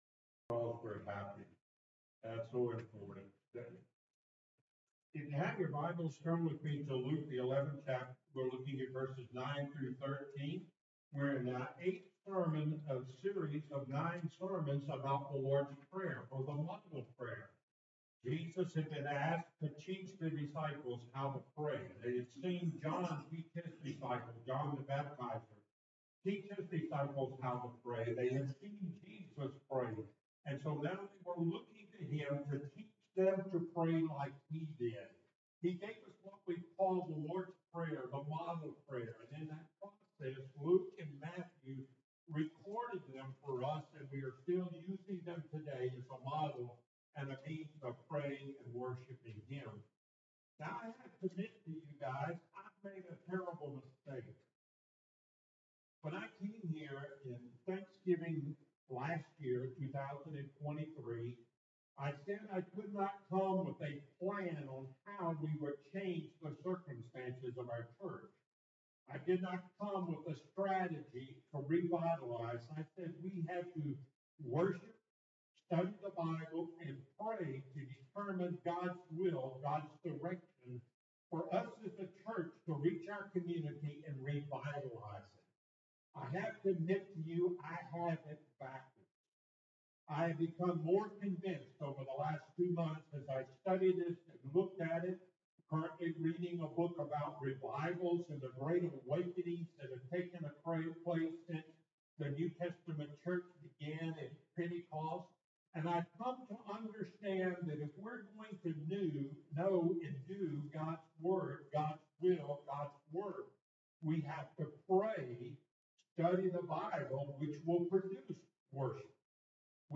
Luke 11:9-13 Service Type: Sunday Morning Topics